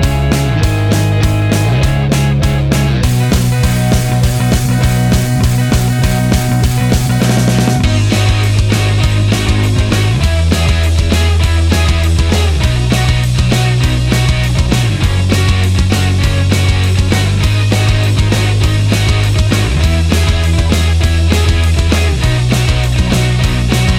no Backing Vocals Rock 3:54 Buy £1.50